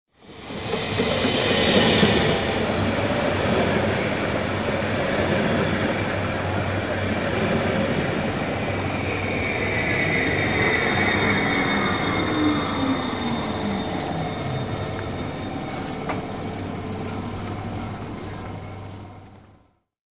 دانلود آهنگ قطار 1 از افکت صوتی حمل و نقل
دانلود صدای قطار 1 از ساعد نیوز با لینک مستقیم و کیفیت بالا